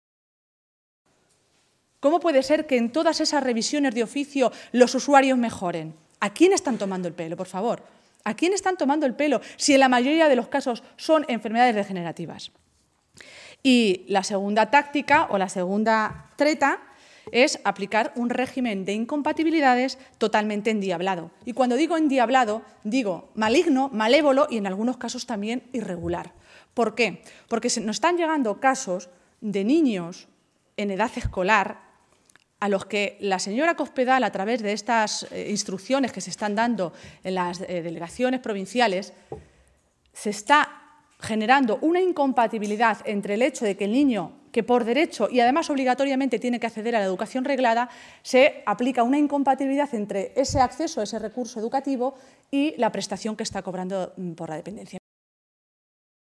Maestre se pronunciaba de esta manera esta mañana, en Toledo, en una comparecencia ante los medios de comunicación minutos antes de que el consejero de Sanidad y Asuntos Sociales ofreciera una rueda de prensa para hacer balance de la aplicación de la Ley de la Dependencia en la región a lo largo del pasado año 2013.